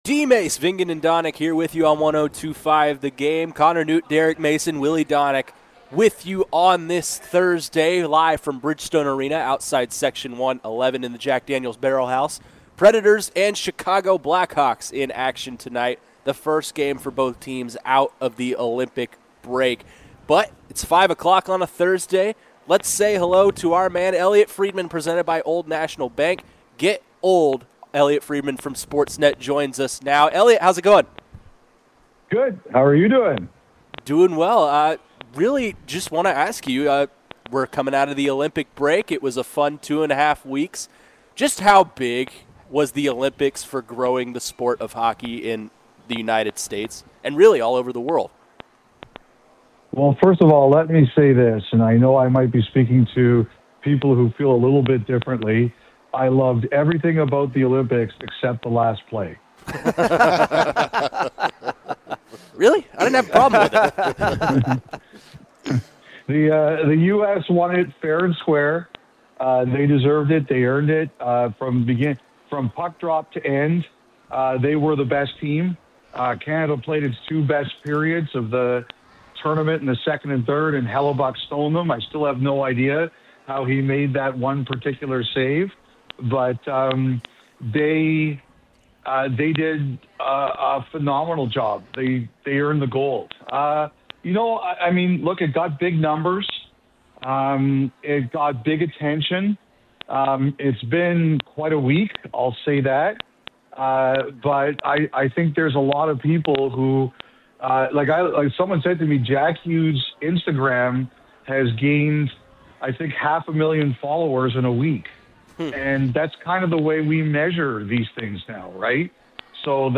NHL Insider Elliotte Friedman joins DVD to discuss the NHL's return from the Olympic break, USA Hockey, what he has heard with the trade deadline coming up, and more